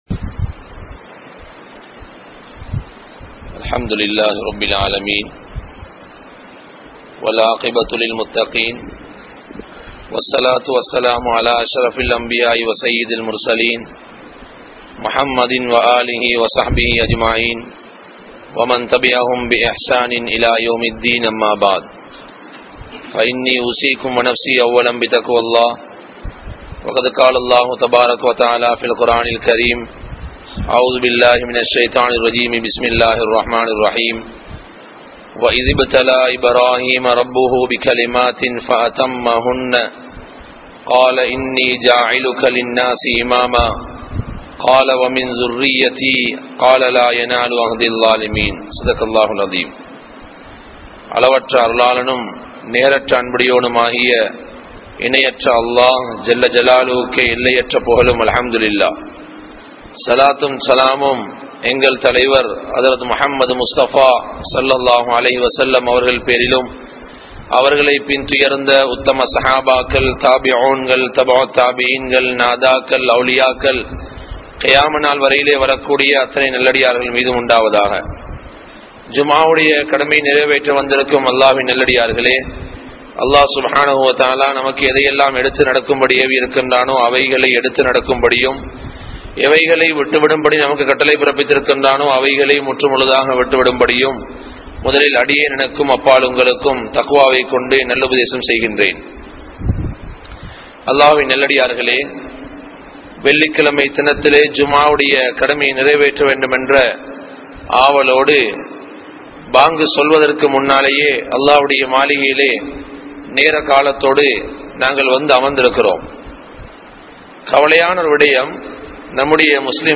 Allah`vin Niumath (அல்லாஹ்வின் நிஃமத்) | Audio Bayans | All Ceylon Muslim Youth Community | Addalaichenai